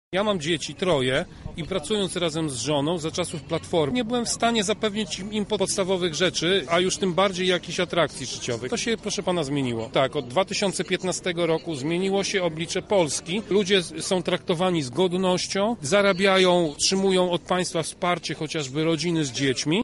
O zysku jaki odniosły rodziny mówi Radny Miasta Lublin Tomasz Pitucha